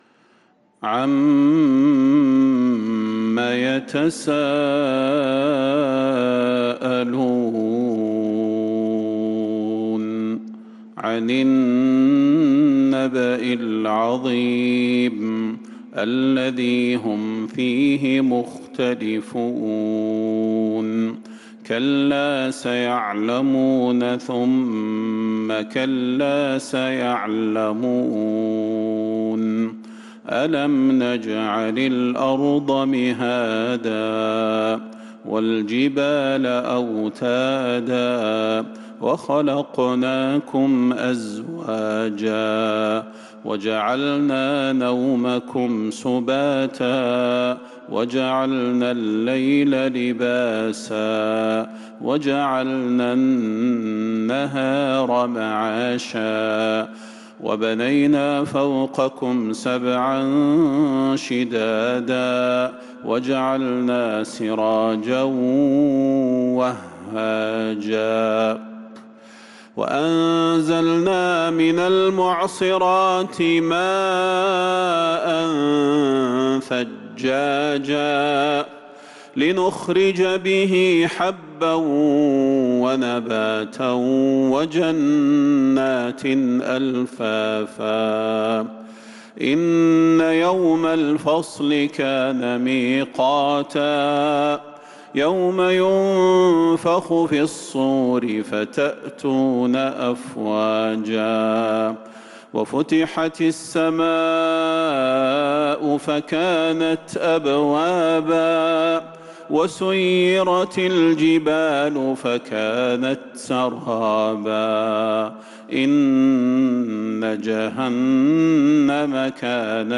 سورة النبأ | جمادى الأولى 1447هـ > السور المكتملة للشيخ صلاح البدير من الحرم النبوي 🕌 > السور المكتملة 🕌 > المزيد - تلاوات الحرمين